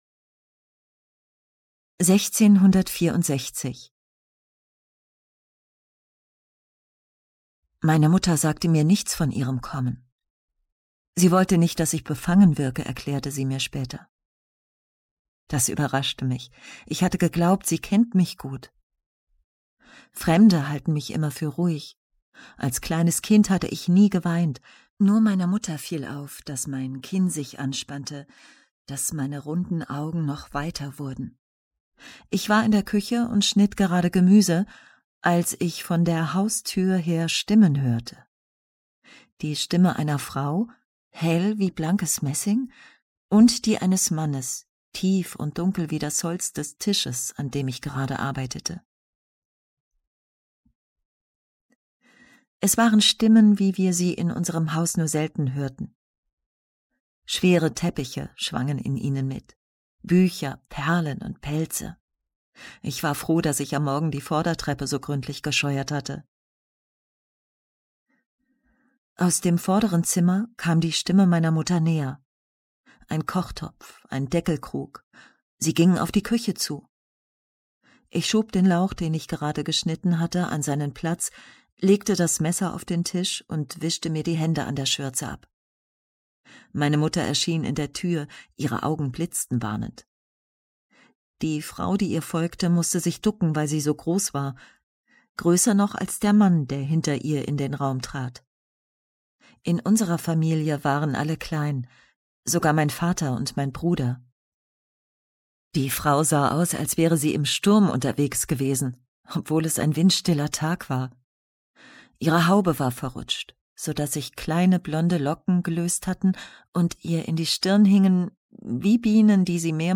sehr variabel, markant
Mittel plus (35-65)
Lip-Sync (Synchron)